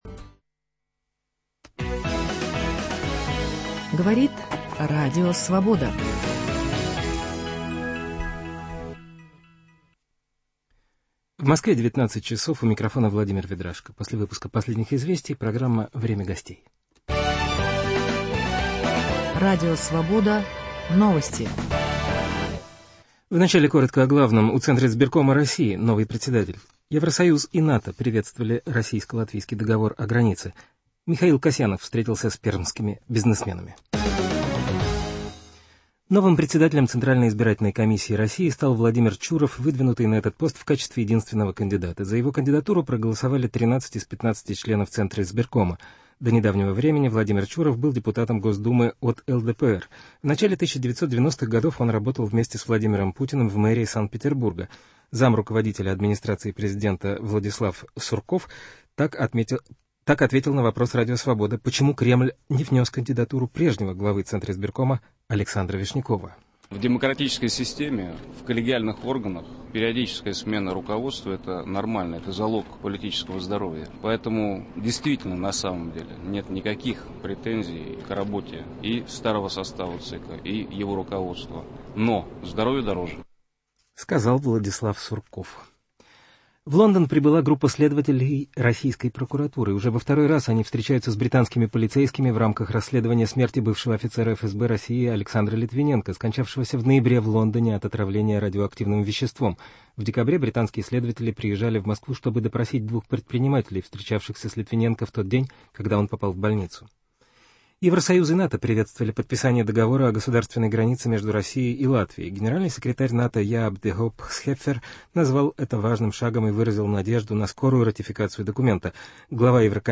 Участвуют: генеральный секретарь партии «Справедливая Россия» Николай Левичев и один из лидеров Левого фронта Илья Пономарев.